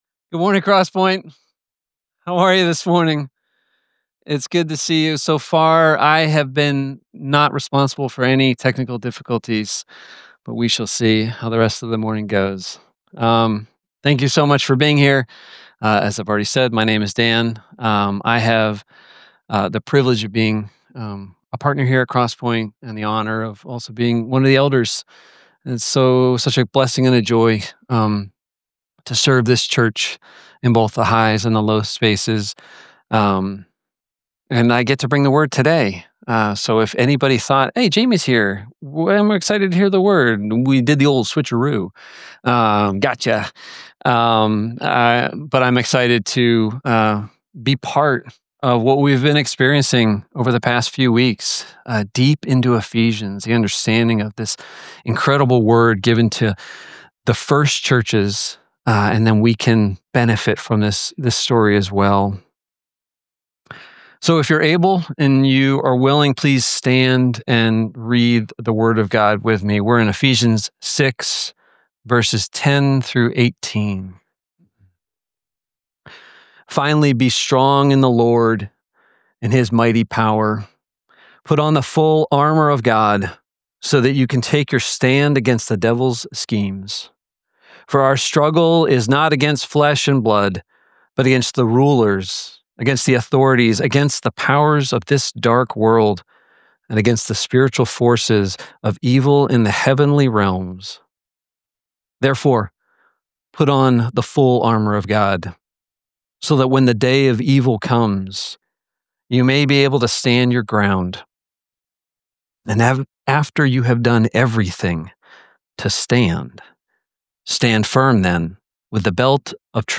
6.29.25 Sermon Only-mastered.mp3